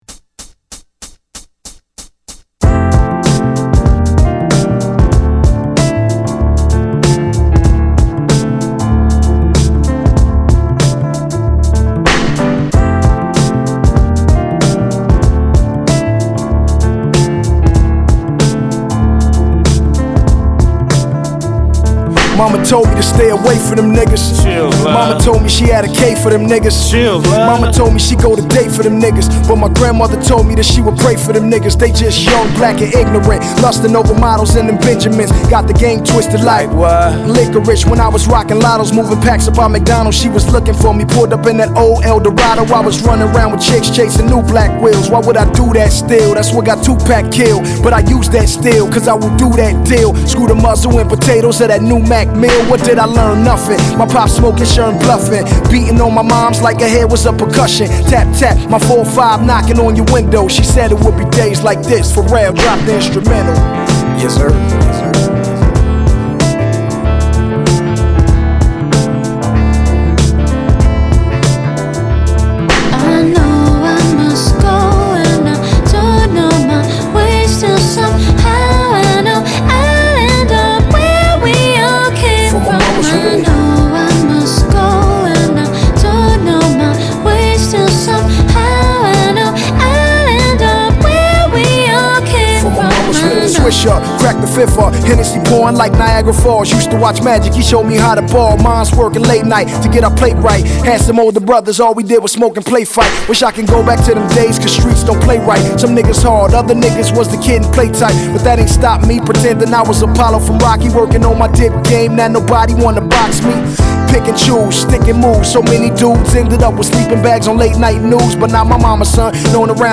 The scenic production continues
airy vocals work perfect on these rhythms